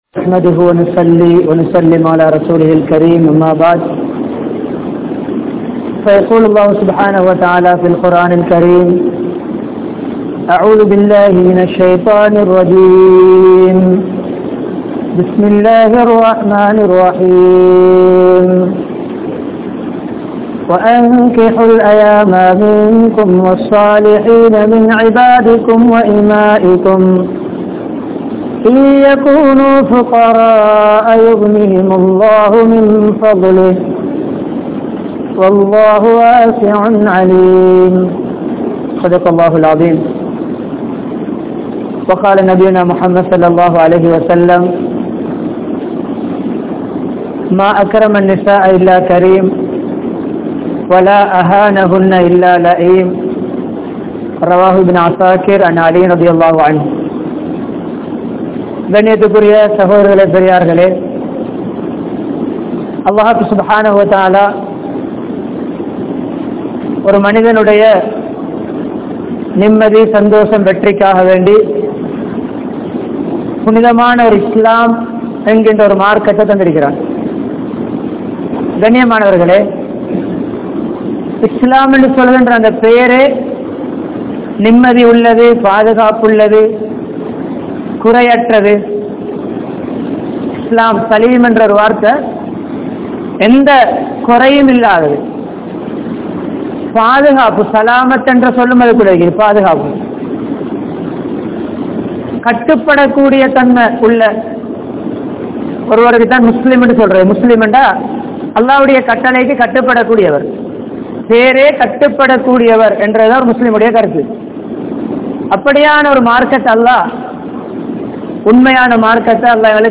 Islaathil Thirumanathin Avasiyam (இஸ்லாத்தில் திருமணத்தின் அவசியம்) | Audio Bayans | All Ceylon Muslim Youth Community | Addalaichenai
Colombo 15, Mattakkkuliya, Koombikala, Al-Masjidhul Ilaahi